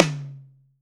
TOM 1H    -L.wav